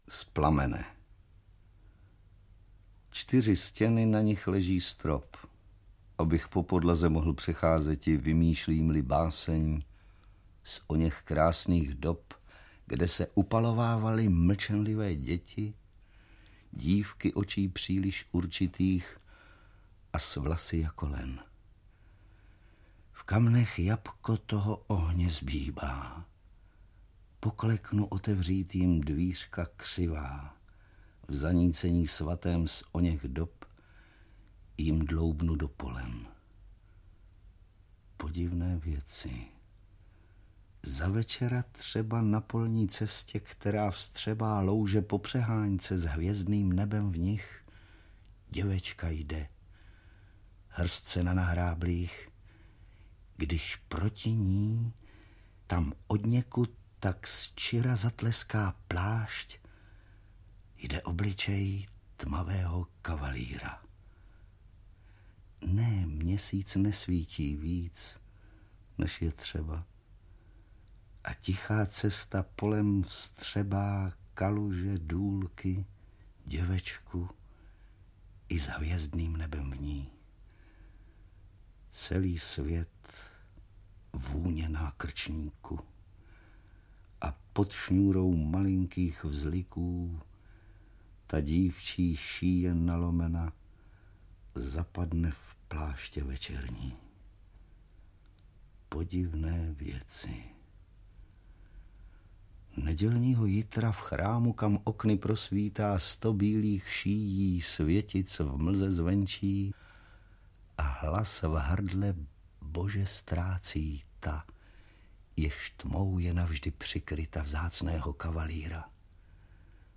Český sen a jiné básně audiokniha
Ukázka z knihy
• InterpretVladimír Brabec, Petr Haničinec, Jaromír Spal, Otakar Brousek st., Jiřina Švorcová